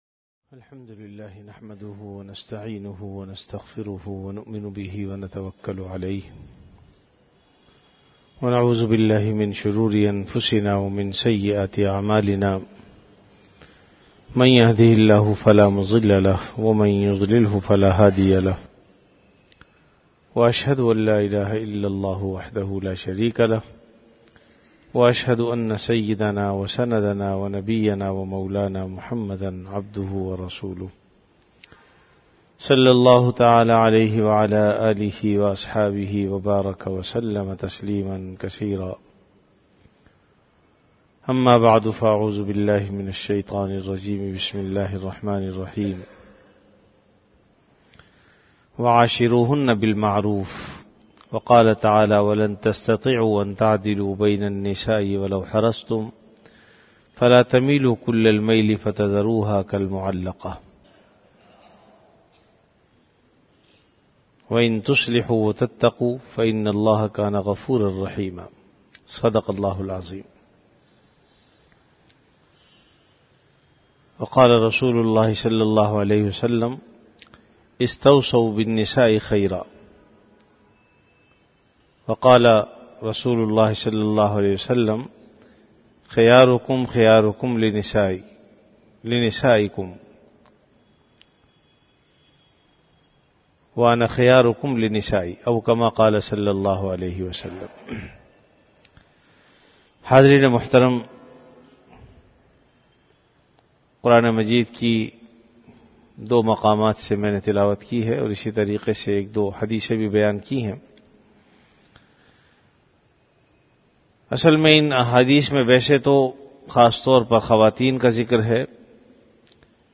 An Islamic audio bayan
Delivered at Jamia Masjid Bait-ul-Mukkaram, Karachi.